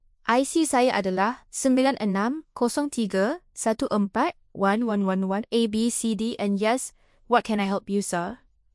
singaporean-0.6b.mp3 · mesolitica/Malaysian-TTS-0.6B-v1 at main
singaporean-0.6b.mp3